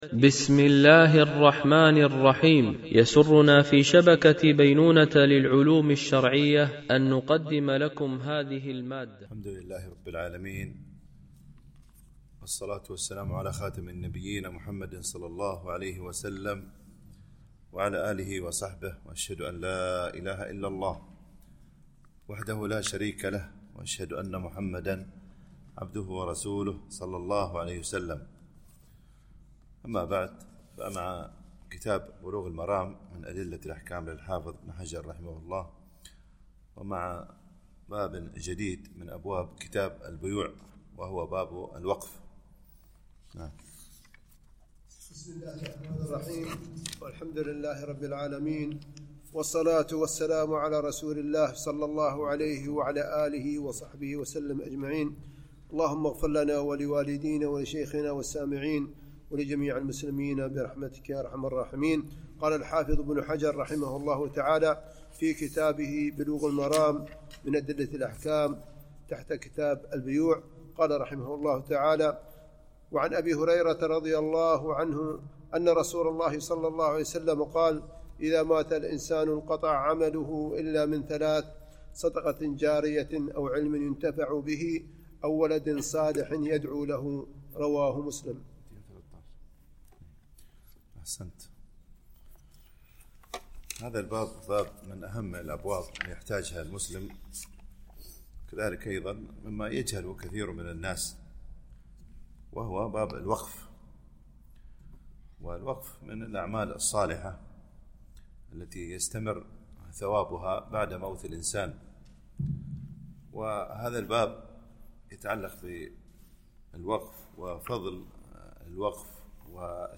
شرح بلوغ المرام من أدلة الأحكام - الدرس 214 ( كتاب البيوع - الجزء ٤٨ - الحديث 927 )